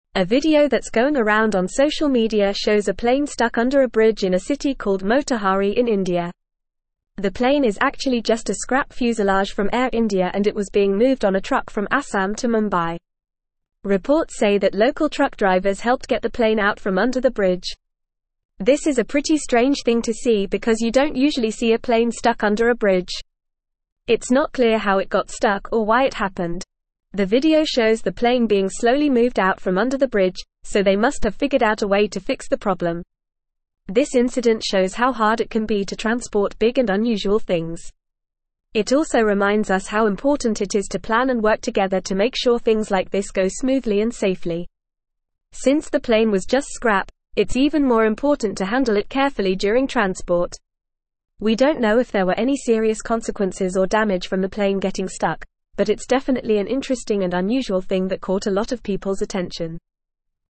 Fast
English-Newsroom-Upper-Intermediate-FAST-Reading-Plane-gets-stuck-under-bridge-in-India.mp3